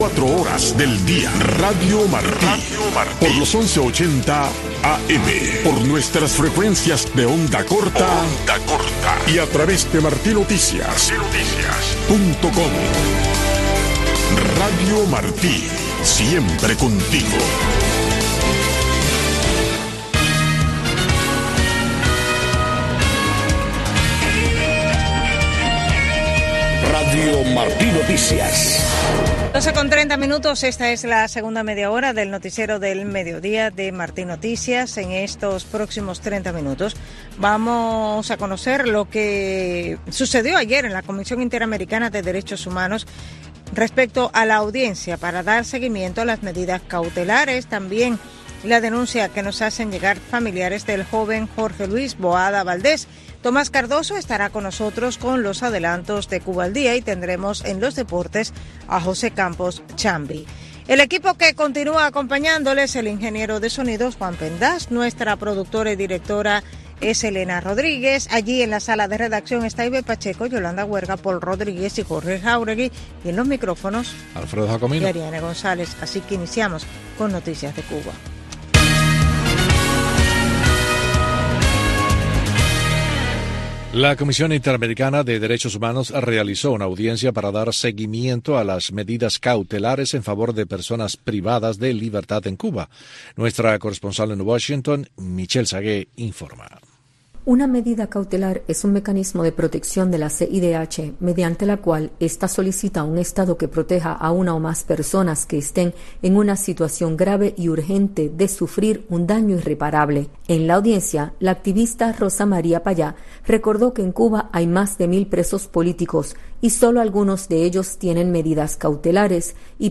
Noticiero de Radio Martí 12:00 PM | Segunda media hora